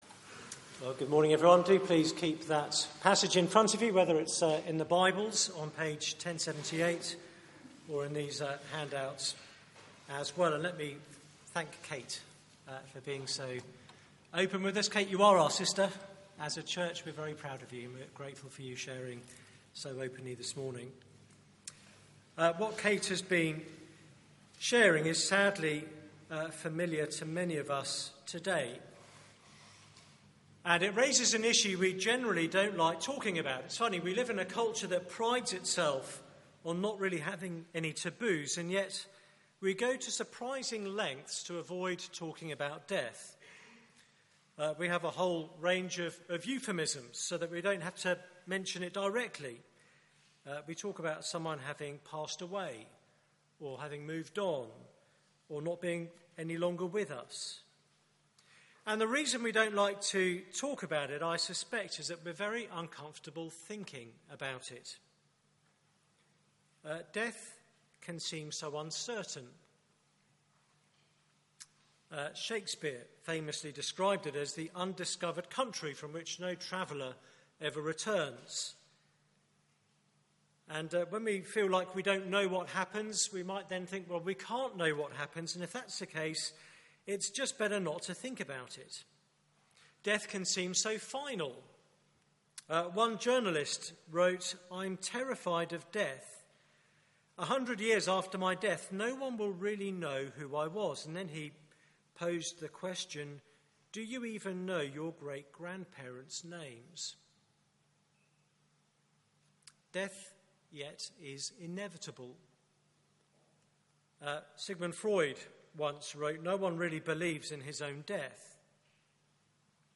John 11:17-44 Service Type: Weekly Service at 4pm « What do You Want Jesus to do for You?